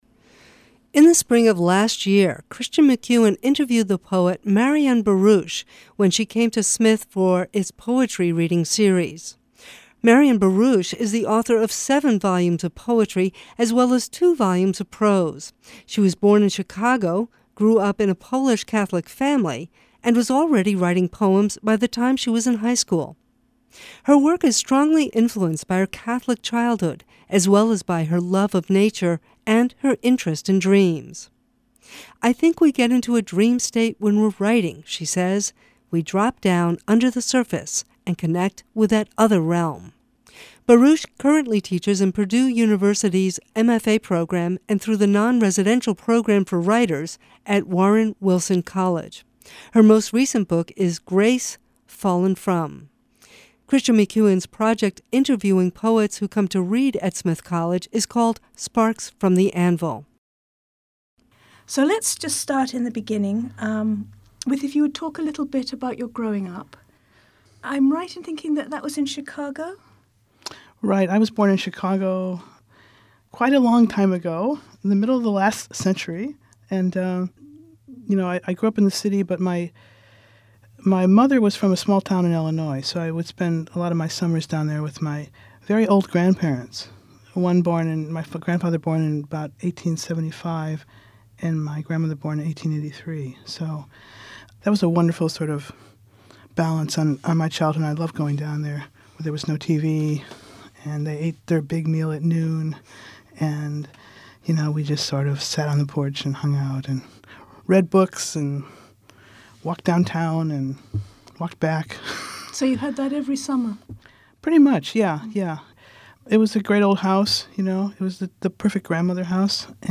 The extended interviews are a web exclusive feature.